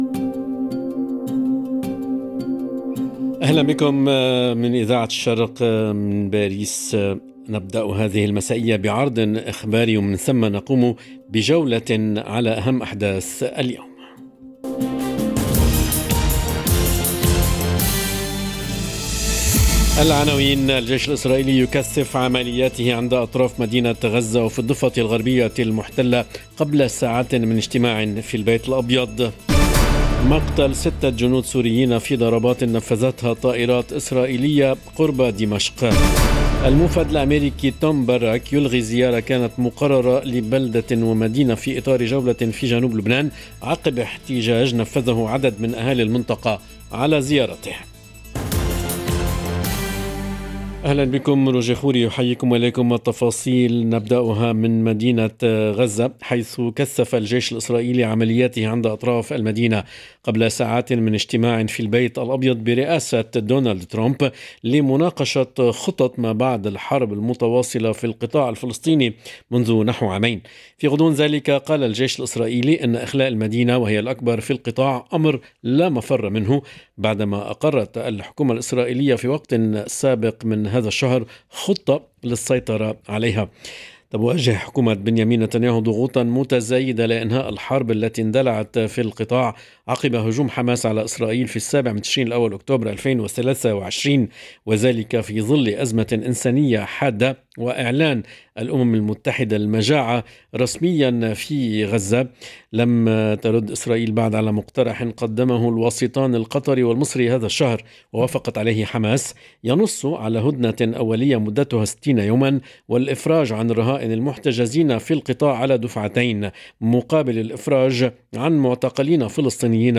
نشرة أخبار المساء: الجيش الإسرائيلي يكثّف عملياته عند أطراف مدينة غزة وفي الضفة الغربية المحتلة قبل ساعات من اجتماع في البيت الأبيض... - Radio ORIENT، إذاعة الشرق من باريس